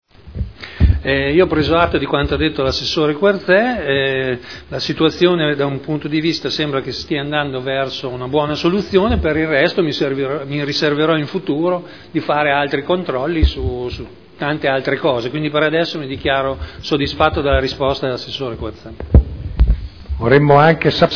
Vittorio Ballestrazzi — Sito Audio Consiglio Comunale